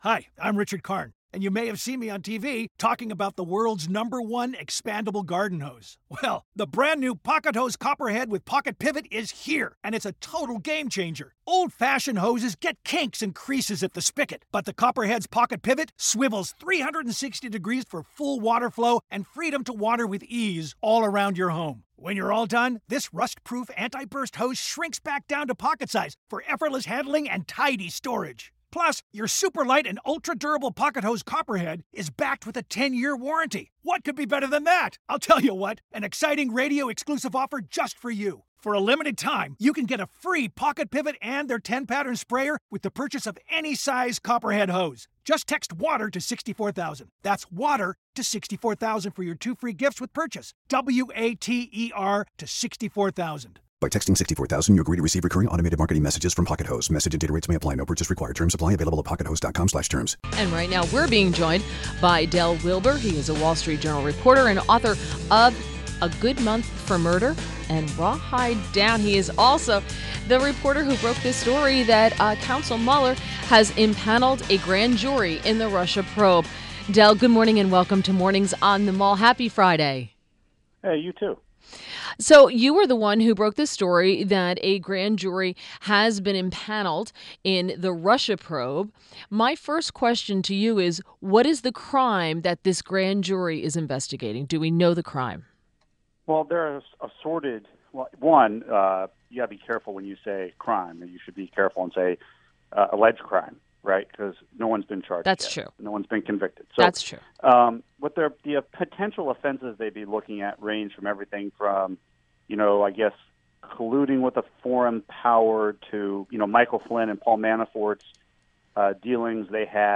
WMAL Interview